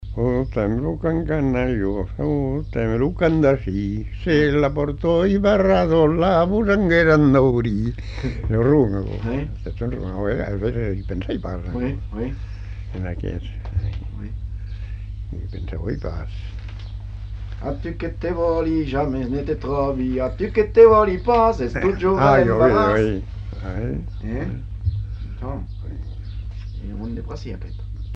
Lieu : Simorre
Genre : chant
Type de voix : voix d'homme
Production du son : chanté
Danse : rondeau